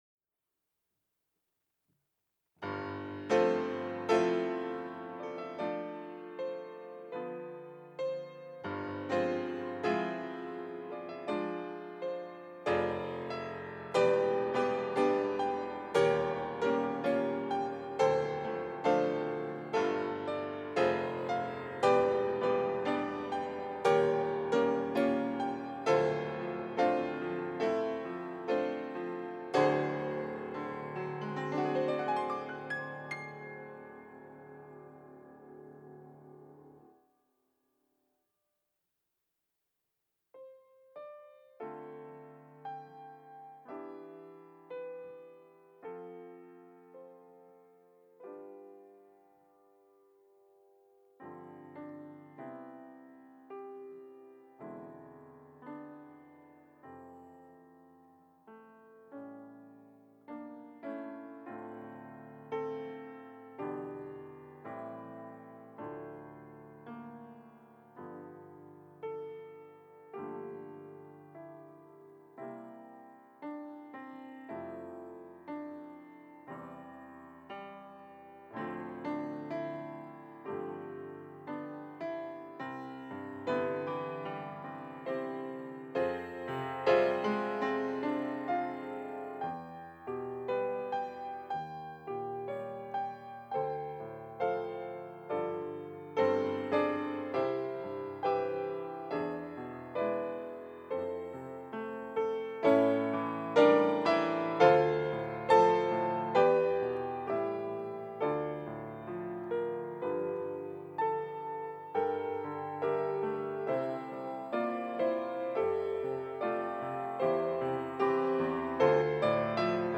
특송과 특주 - 참 아름다워라